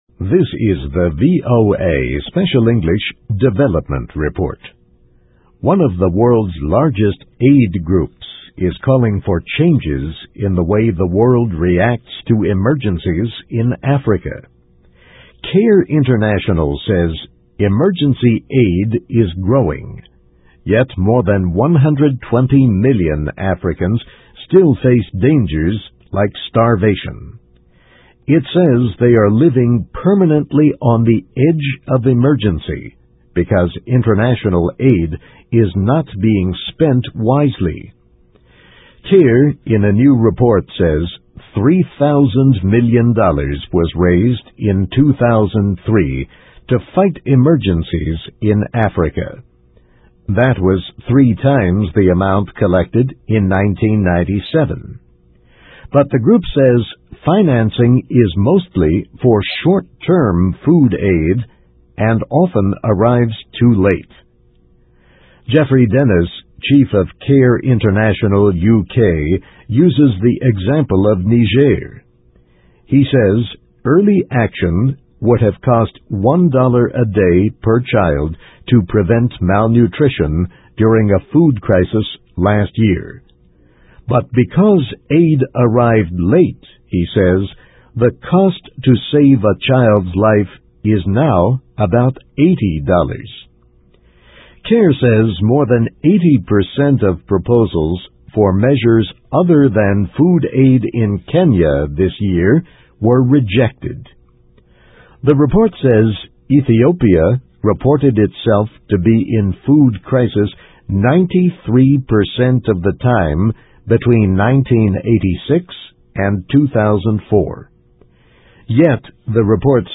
Voice of America Special English